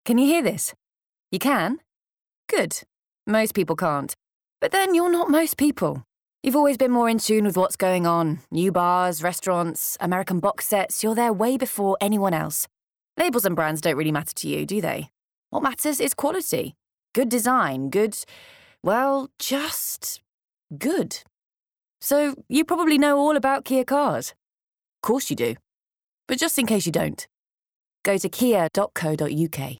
Smooth, Warm and Natural to Light, Friendly and Conversational. 20-30.
Commercial, Natural, Conversational, Light
Straight